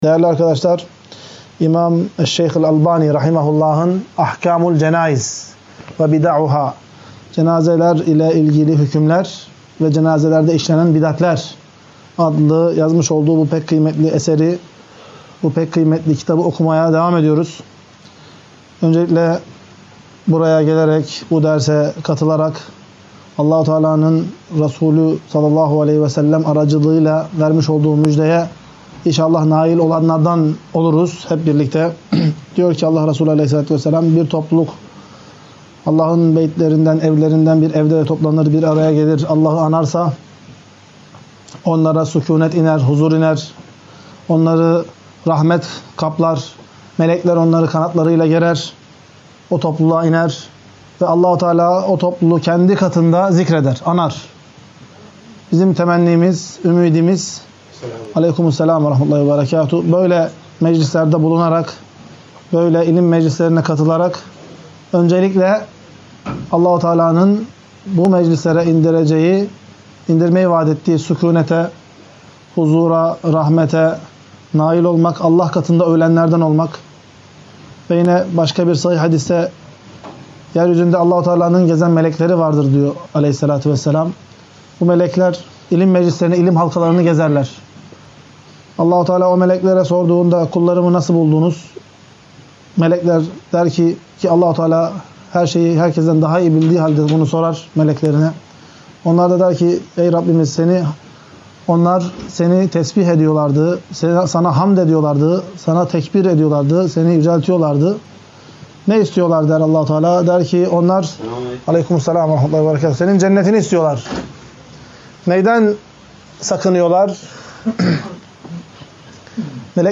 22. Ders - CENÂZE AHKÂMI VE CENÂZEDE YAPILAN BİDATLER - Taybe İlim